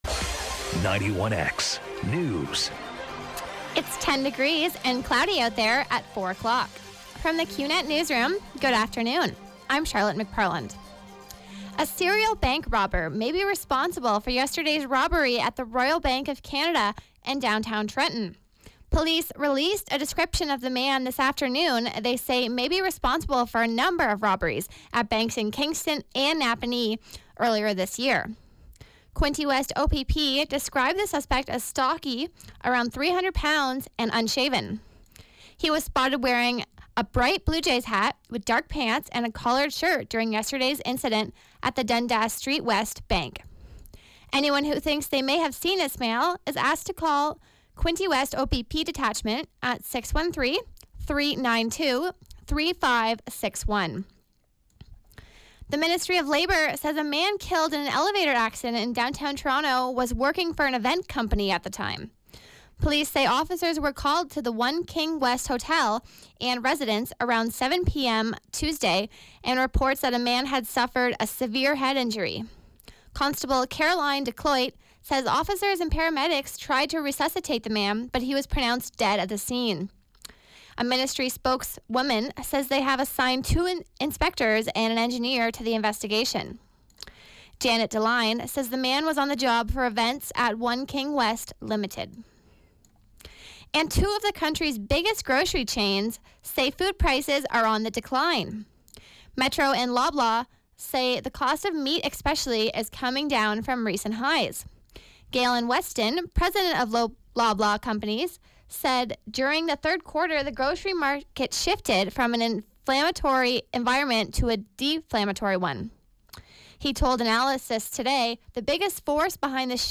91X Newscast- Wednesday, Nov. 16, 2016, 4 p.m.